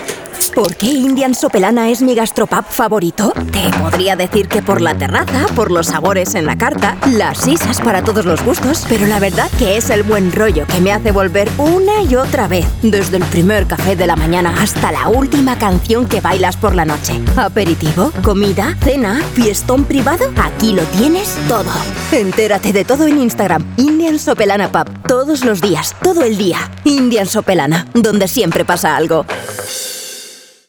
Mi estudio es mi centro de operaciones: acústicamente tratado y equipado con hardware profesional para asegurar una calidad impecable, lista para cualquier estándar de emisión.
Locución Publicitaria
Ofrezco el tono enérgico, persuasivo y vibrante que exige tu spot.
CUNA_ENERGICO_INDIAN_SOPELANA.mp3